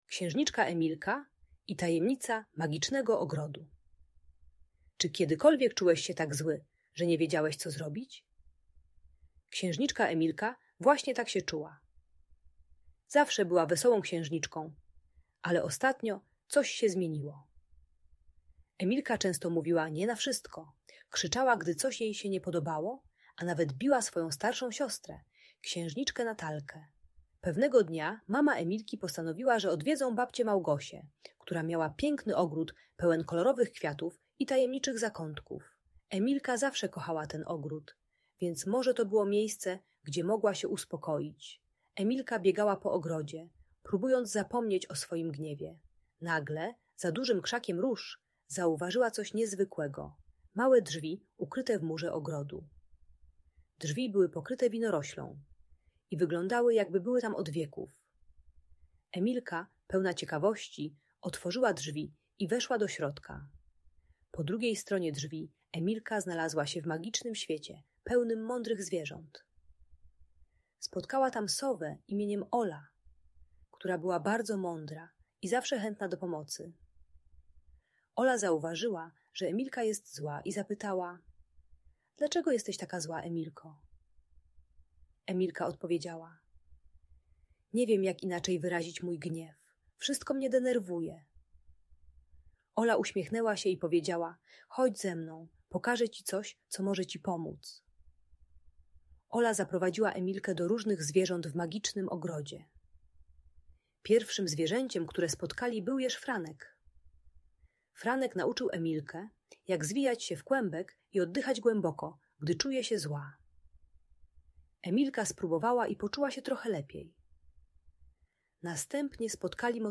Audiobajka o złości i agresji uczy techniki głębokiego oddychania, wyrażania emocji przez ruch oraz nazywania uczuć słowami zamiast bicia.